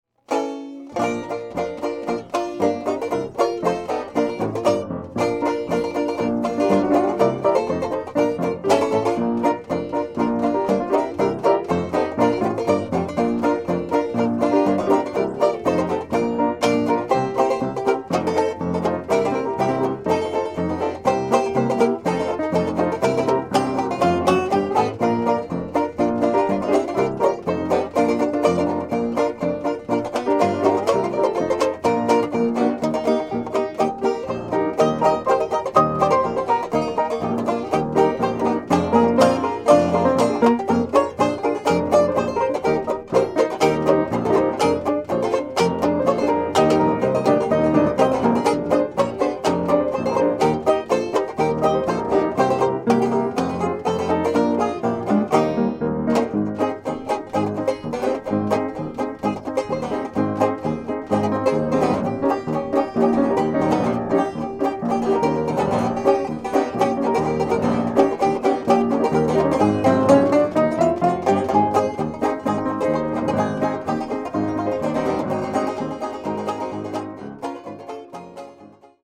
This two CD set of heritage music is a project of the Hampshire County, West Virginia 250th Anniversary Committee and is taken from over eighteen hours of archived music.